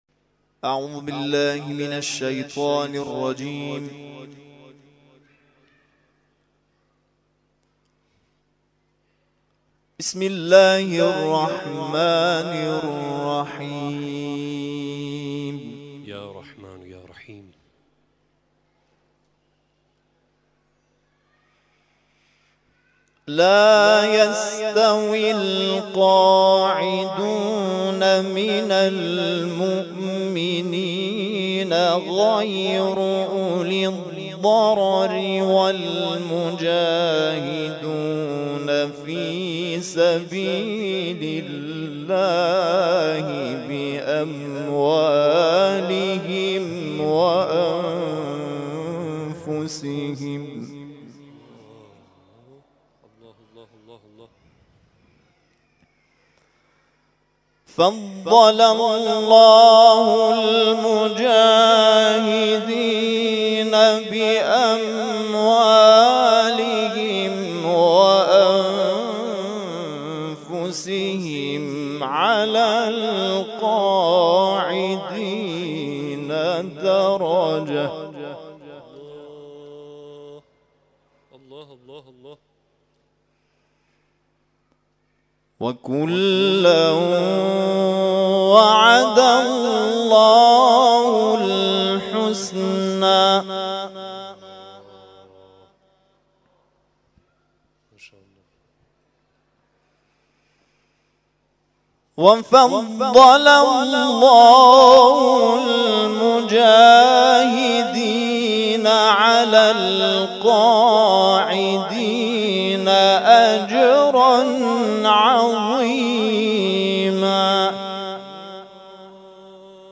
صوت تلاوت‌های 23 تیر آستان عبدالعظیم(ع)
گروه جلسات و محافل: محفل انس با قرآن کریم این هفته آستان عبدالعظیم الحسنی(ع) با تلاوت قاریان ممتاز و بین‌المللی کشورمان برگزار شد.